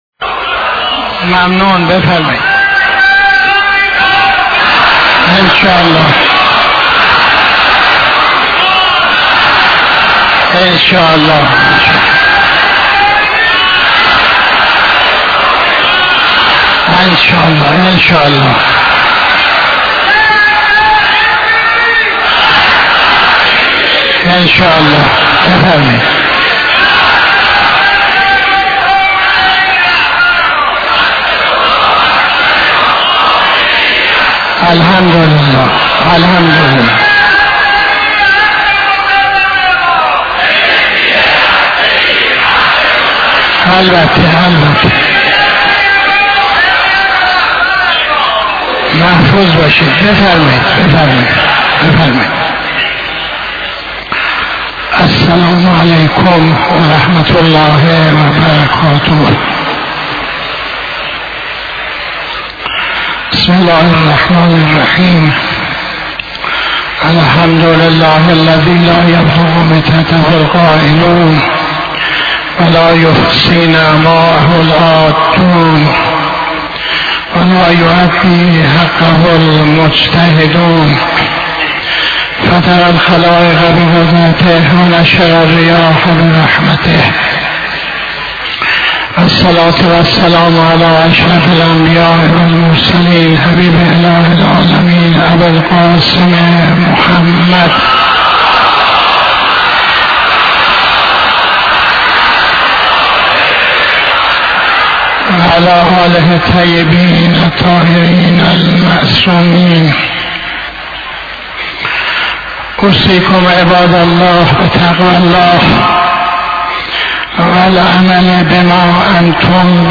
خطبه اول نماز جمعه 11-01-84